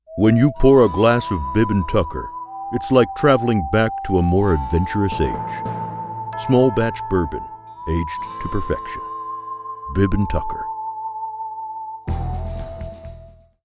Male
Radio Commercials
Words that describe my voice are conversational, trustworthy, authoritative.